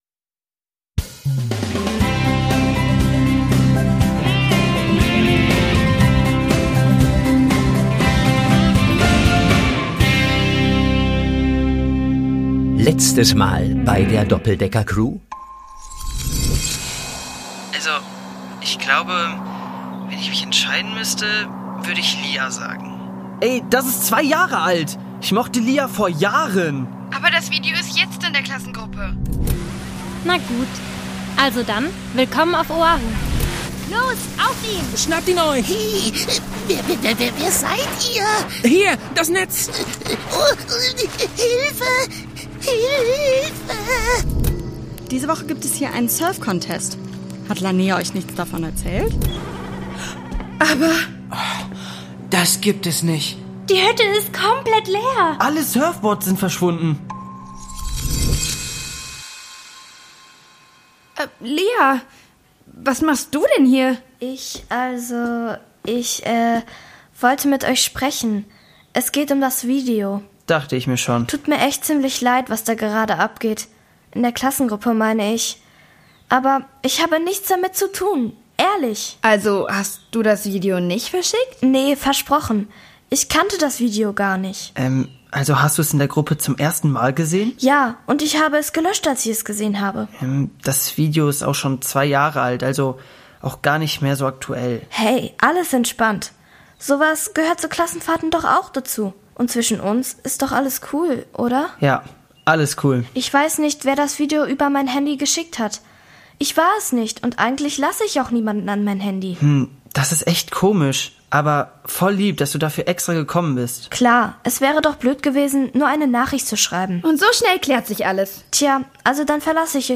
Download - Island 4: Bär auf dem Meer (2/3) | Die Doppeldecker Crew | Hörspiel für Kinder (Hörbuch) | Podbean